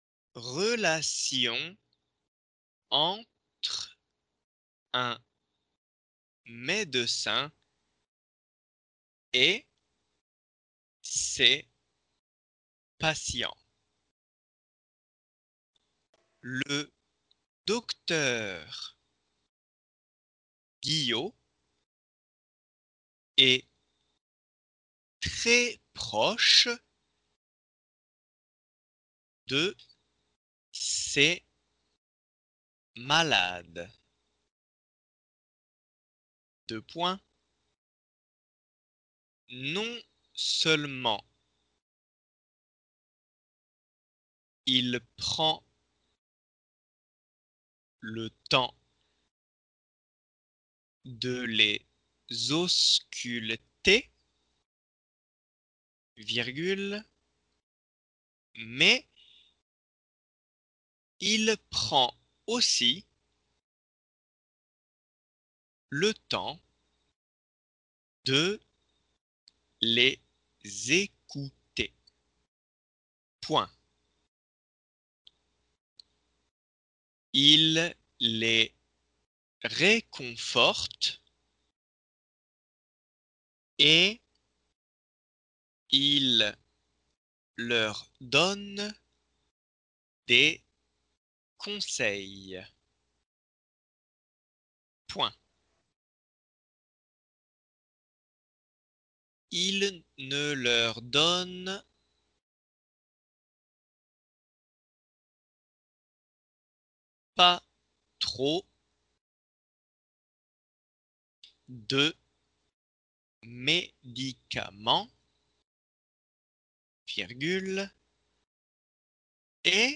仏検２級 デイクテー音声